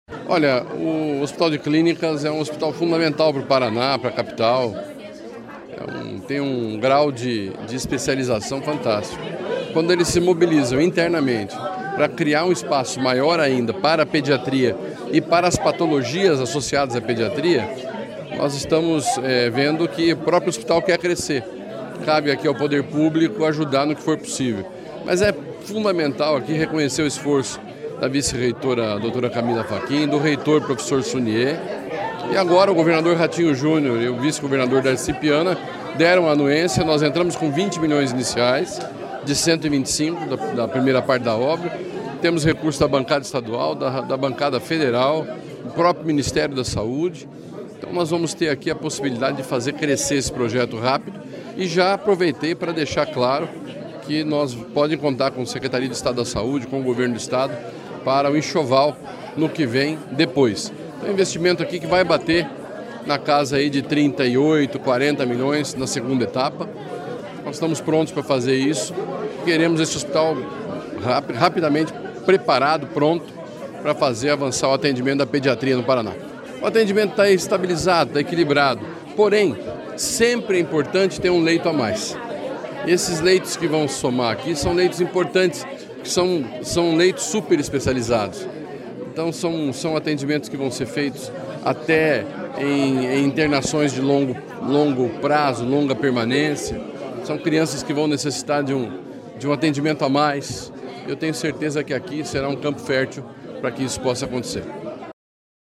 Sonora do secretário da Saúde, Beto Preto, sobre a entrega de R$ 20 milhões para a construção do Instituto Pediátrico do Hospital de Clínicas